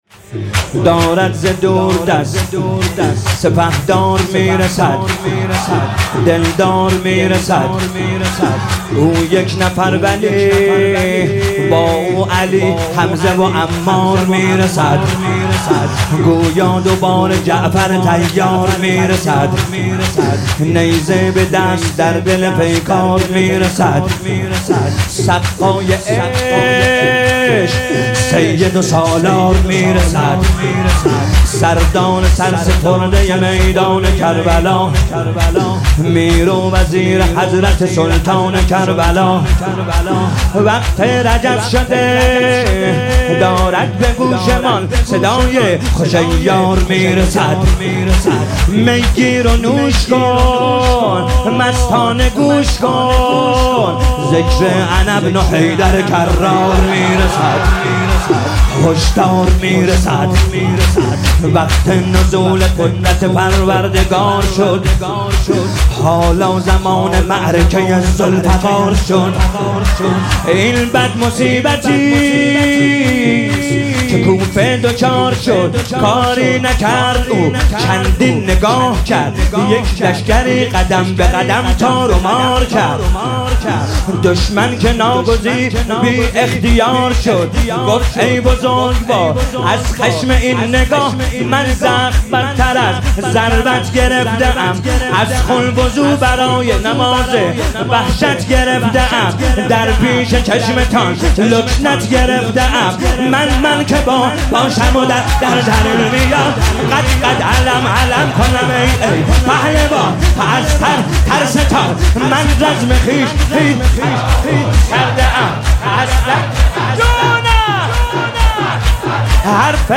شهادت حضرت ام‌البنین سلام‌الله‌علیها